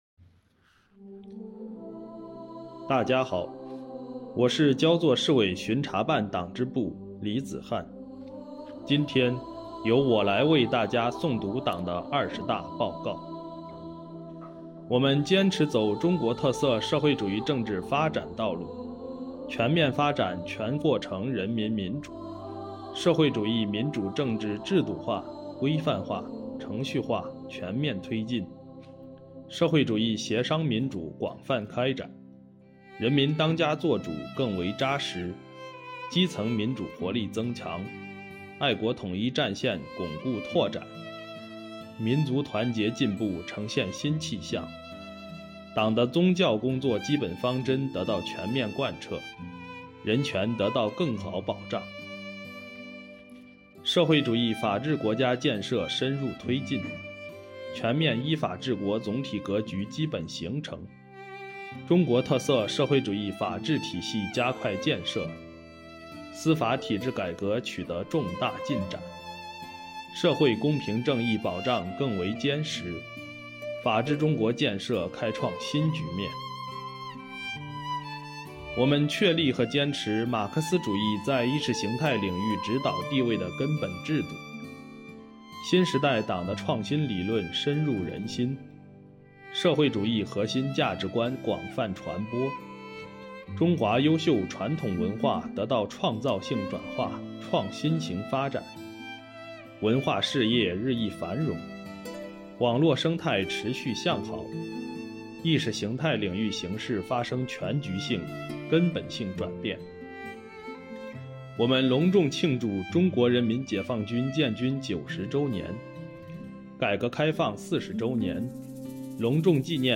本期诵读人